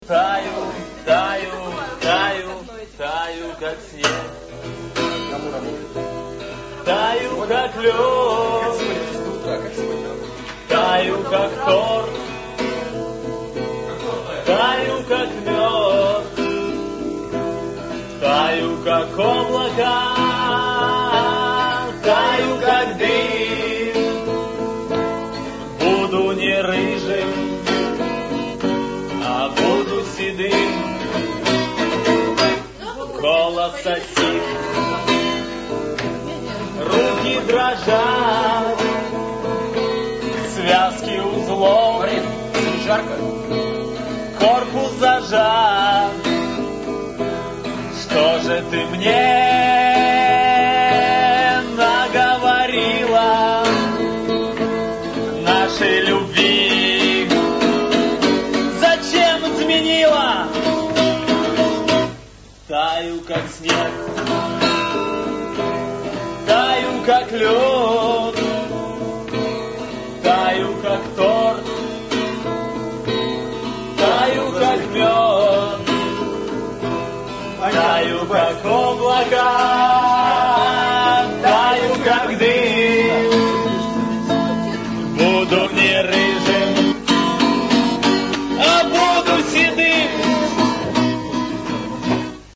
К сожалению у них плохое качество записи, но лучше нету. :-(